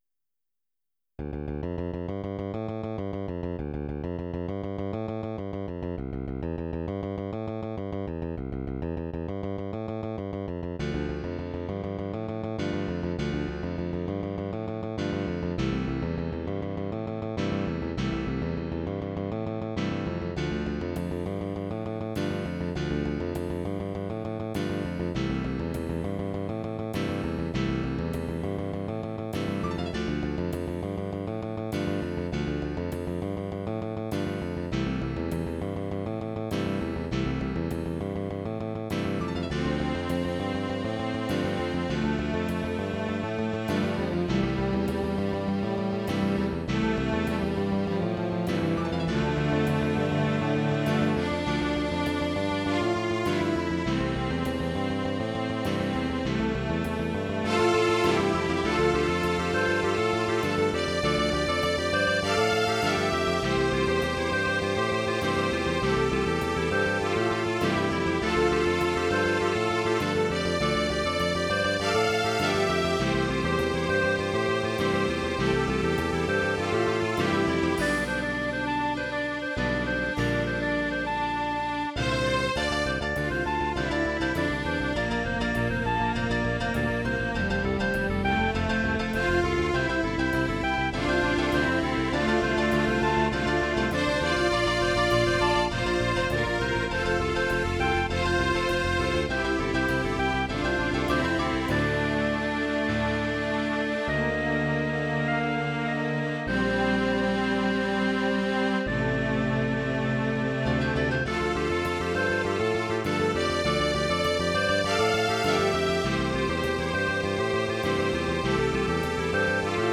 Tags: Piano, Clarinet, Strings, Guitar, Digital
Title Ensemble Opus # 28 Year 0000 Duration 00:04:38 Self-Rating 3 Description Odd instrument mix, I know.